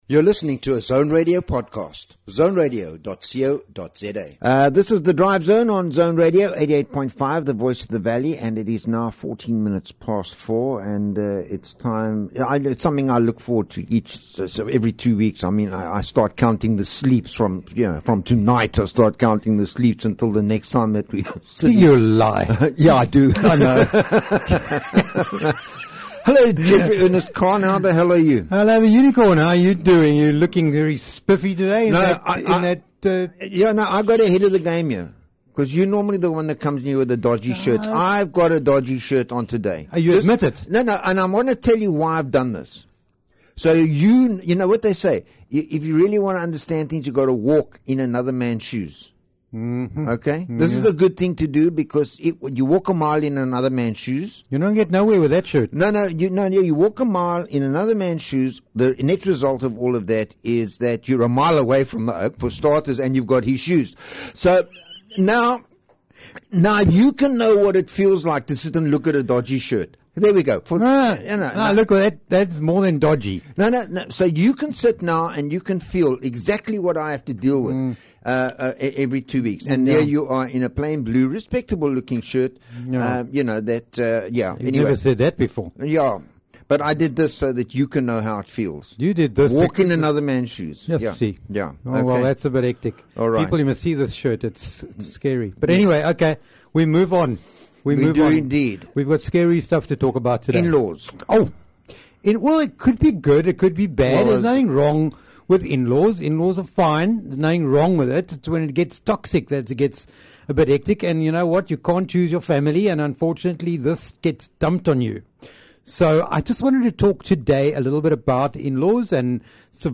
is a bi-weekly radio show exploring the rich tapestry of human experiences. Delve into the sweet and tangy moments of life, savoring conversations on relationships, wellbeing, and the flavors that make up our existence.